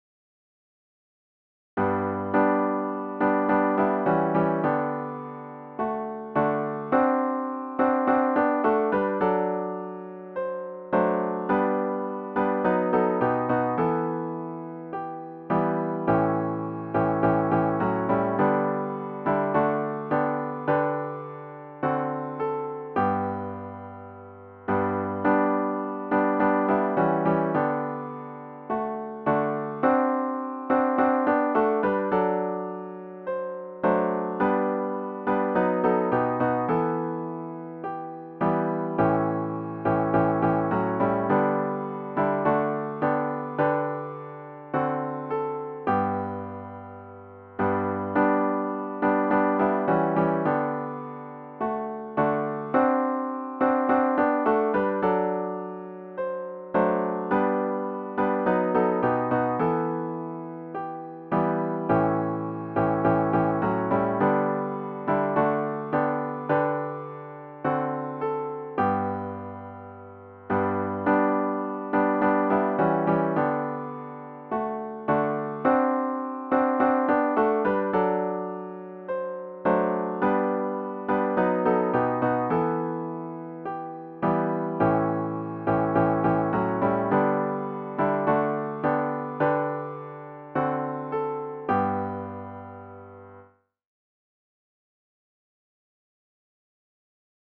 CLOSING HYMN   “O Love That Wilt Not Let Me Go”   GtG 833